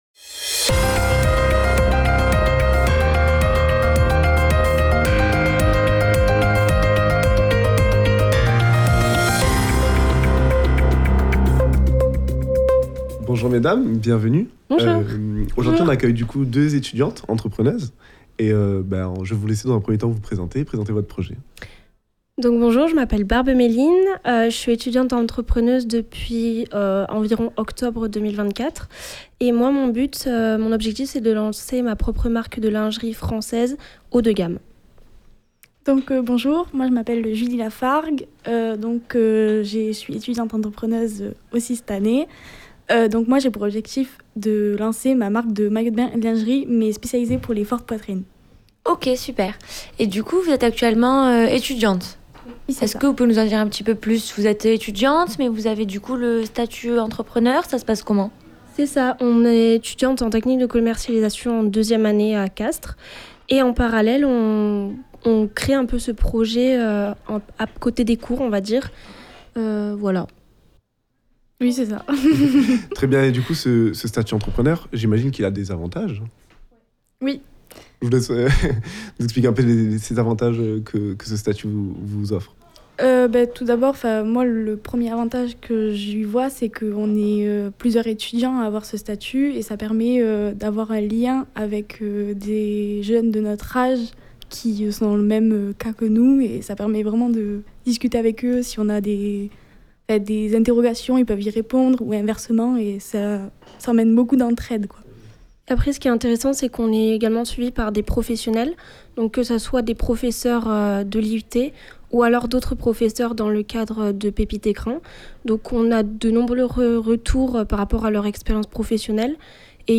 Reportages